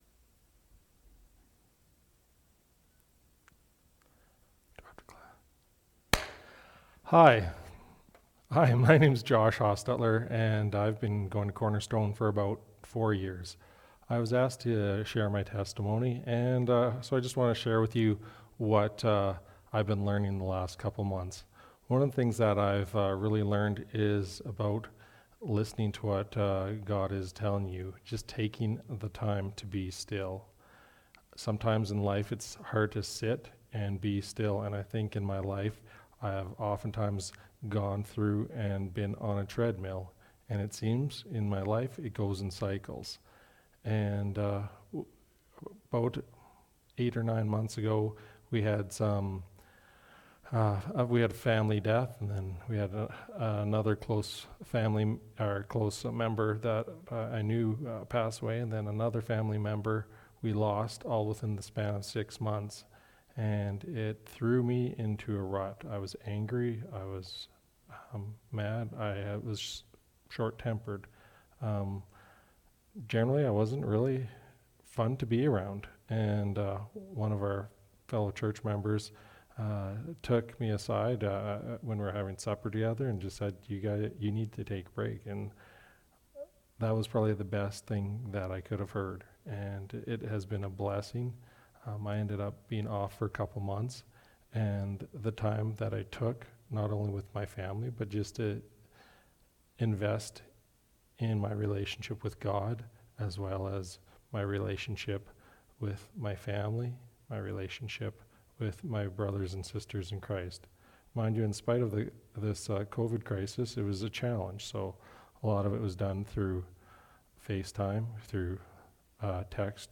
Testimony Service Type: Testimony Speaker